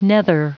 Prononciation du mot nether en anglais (fichier audio)
Prononciation du mot : nether